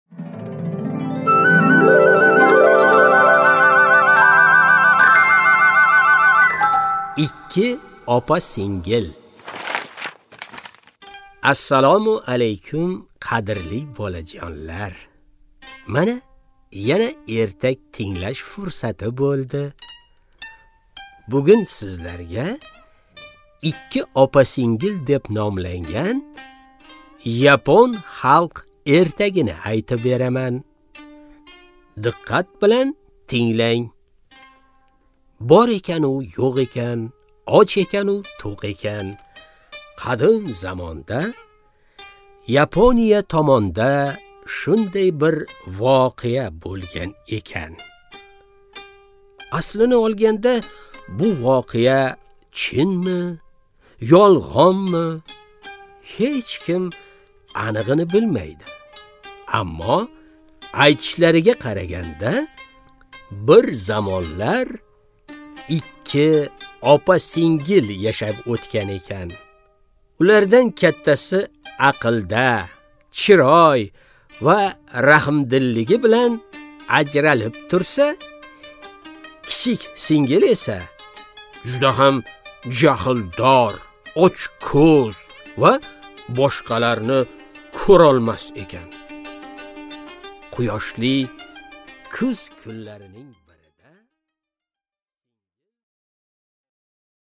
Аудиокнига Ikki opa-singil